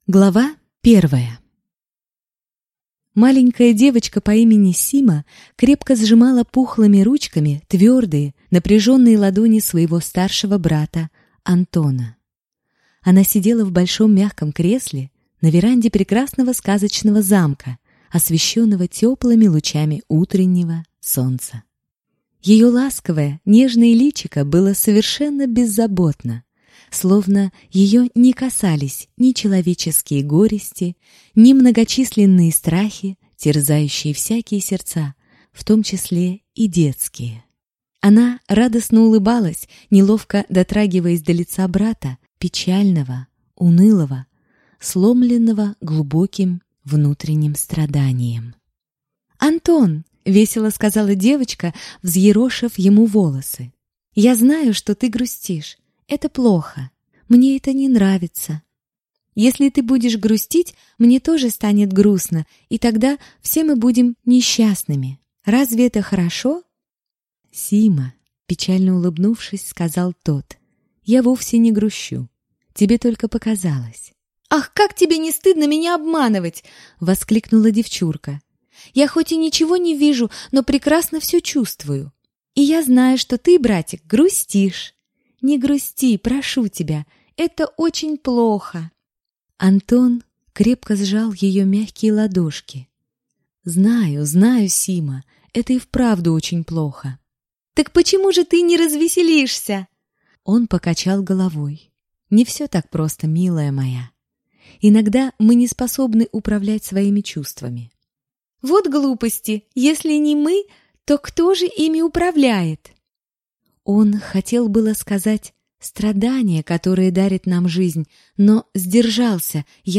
Аудиокнига Тайна Волшебного Камня | Библиотека аудиокниг